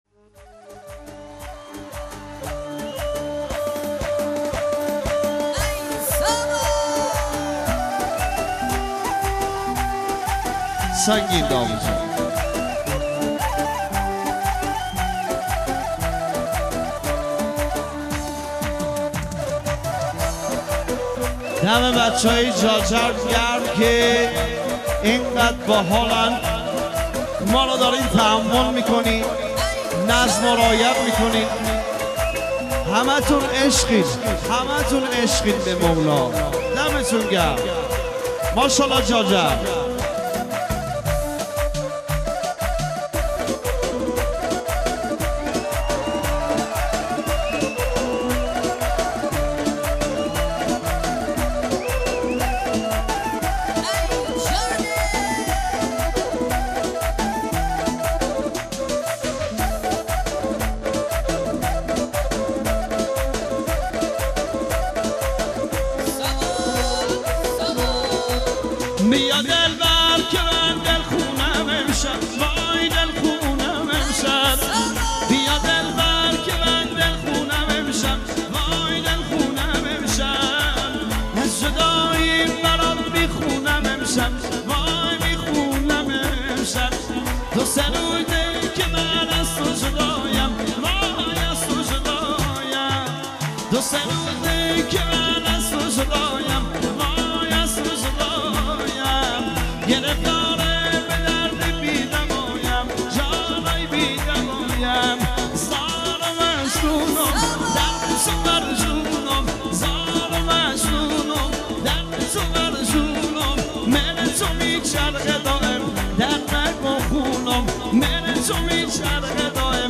آهنگ کرمانجی